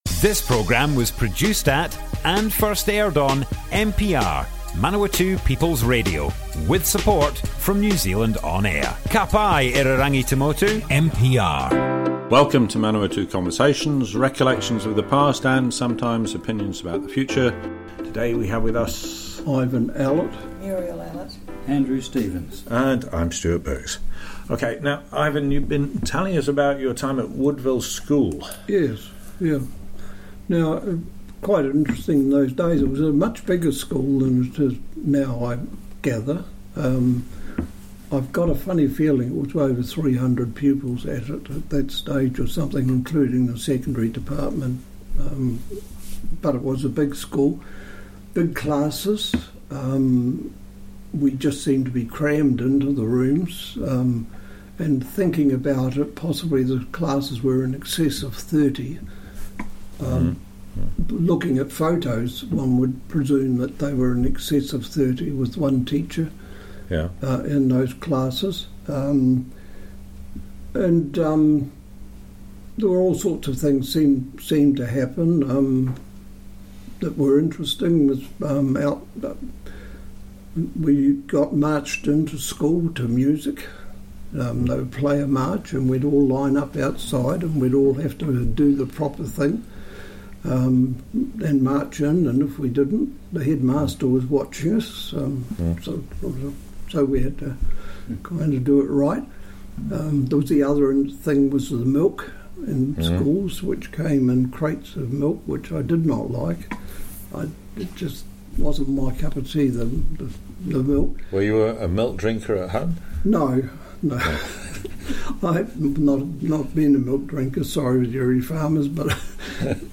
Manawatu Conversations More Info → Description Broadcast on Manawatu People's Radio 7th June 2022.
oral history